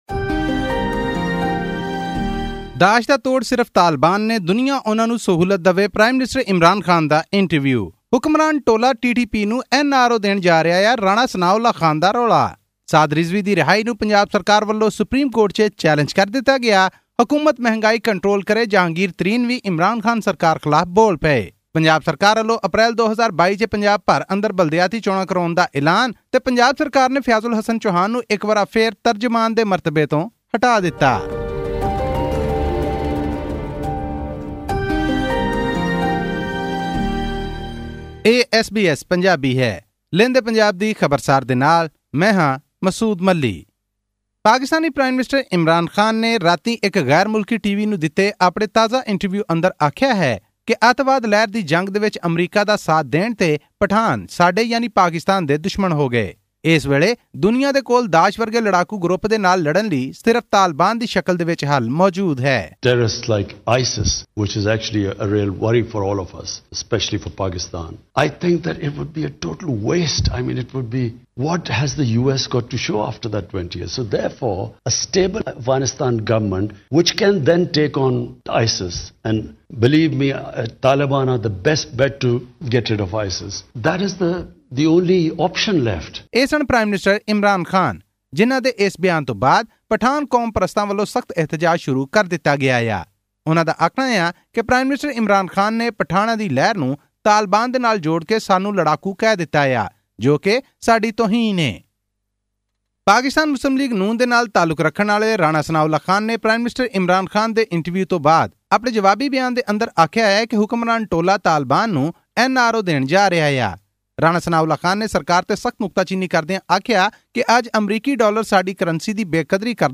Prime Minister Imran Khan has urged world leaders to engage with the Taliban to avoid Afghanistan becoming a terror spot for organisations like ISIS (Islamic State for Iraq and Syria). Tune into this week's news bulletin from Pakistan for all this and more.